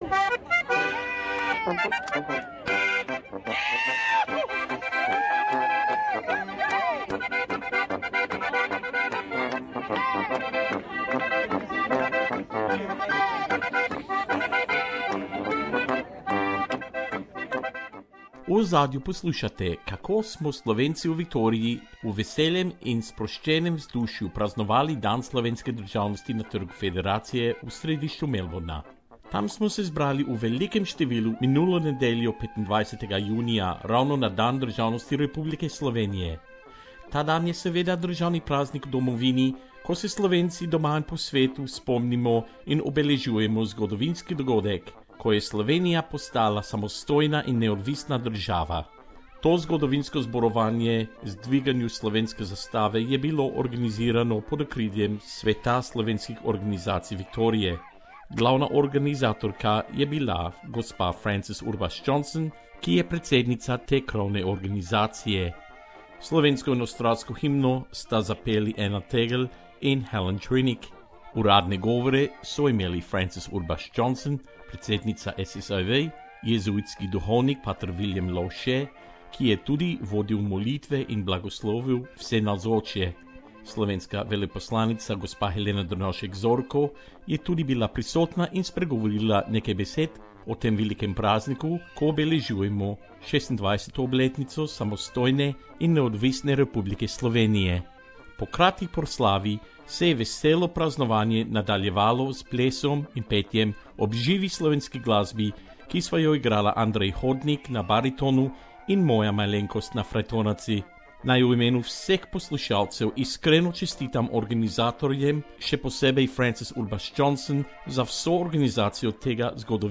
Slovenians in Victoria celebrated Slovenian Statehood Day at Melbourne's Federation Square on Sunday, June 25th, right on the 26th anniversary of Slovenia being declared a sovereign and independent state.
Gathering at Federation Square, Melbourne Source: SBS Slovenian